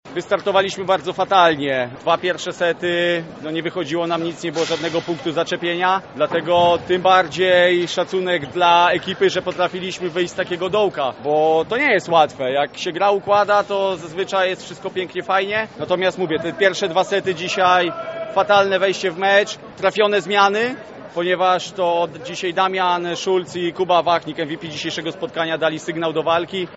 Wywiady pomeczowe